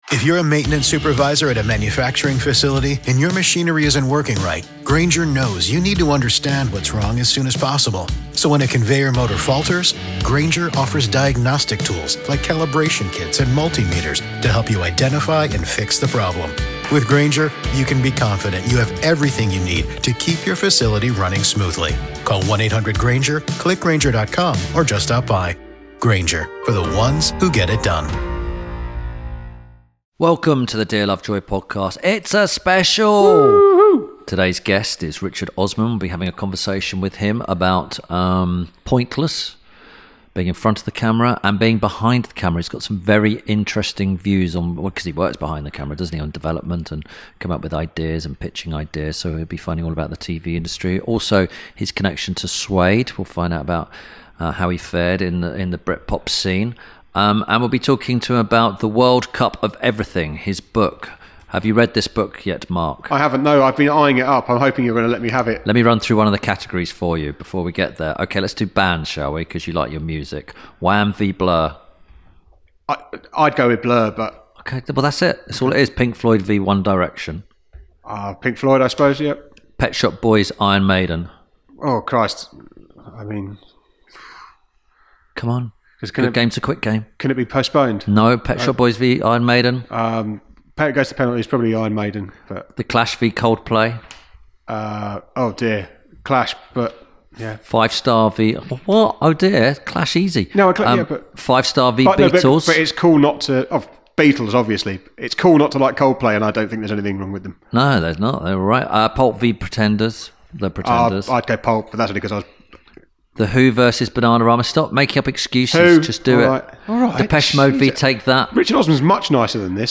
This week Tim Lovejoy talks to producer and presenter, Richard Osman. They discuss Pointless, how he produces television, and Richard’s new book - The World Cup Of Everything.